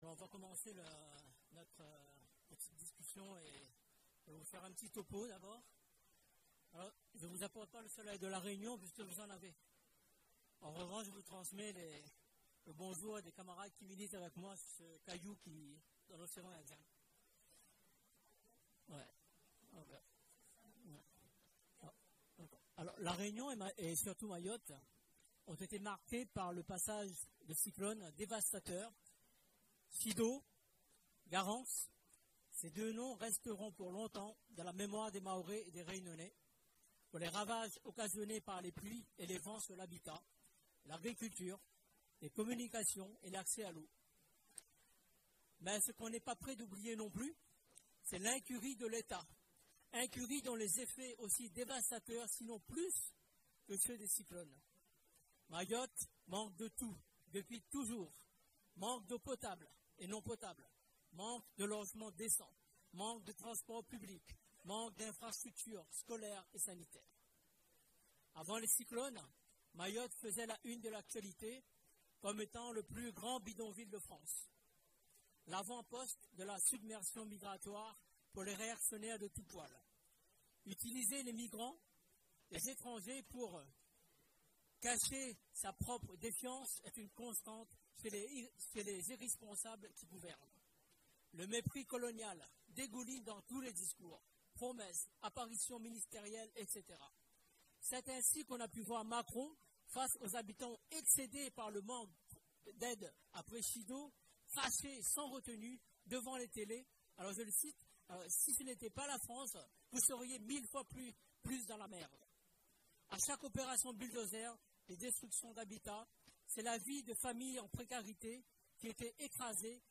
Forum Lutte Ouvrière : Intervention de notre Camarade à la fête de Lutte Ouvrière 2025 à Paris